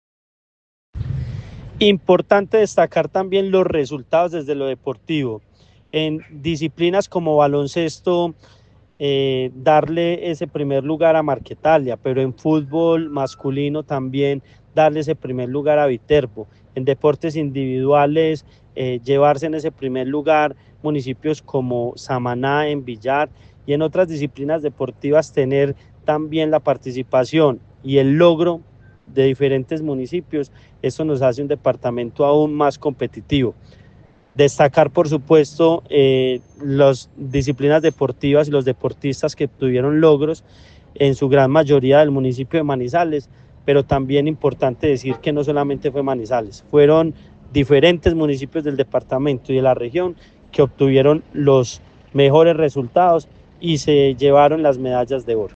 Andrés Duque, secretario de Deporte, Recreación y Actividad Física de Caldas.
andres-duque-osorio-secretario-de-deporte-de-caldas-1.mp3